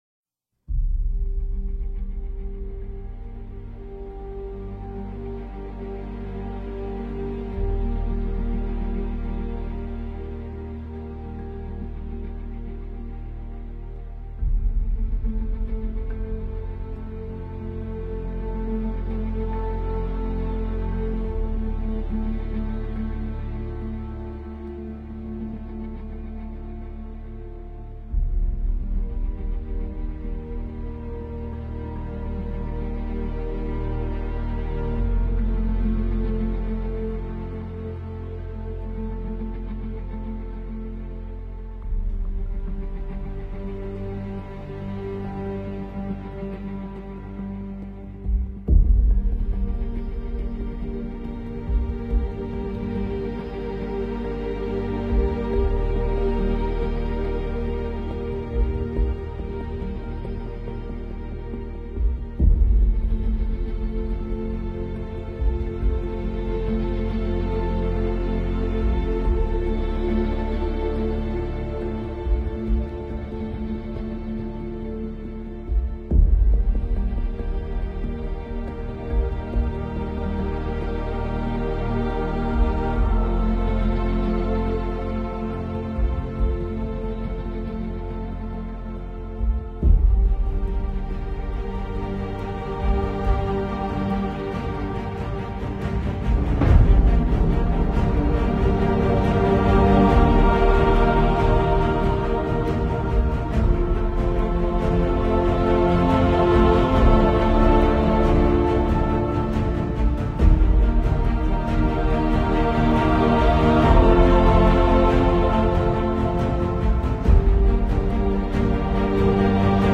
آهنگ حماسی